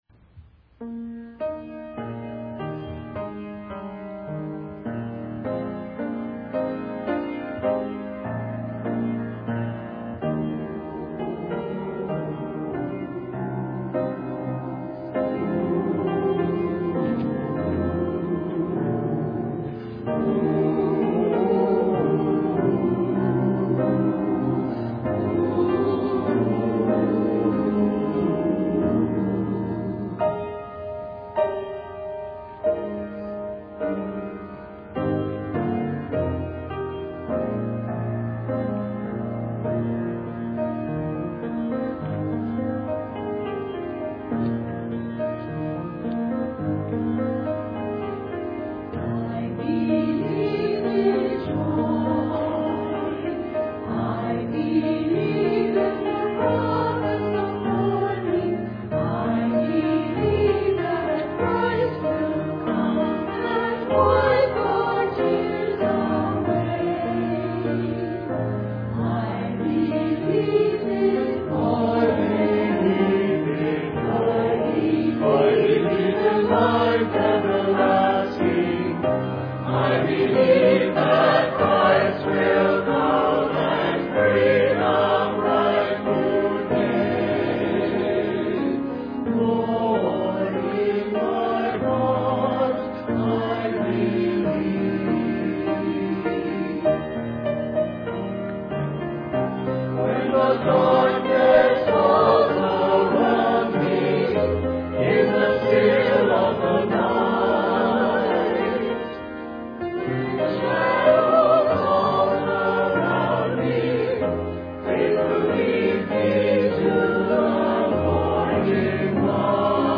Sunday Service
Christmas Cantata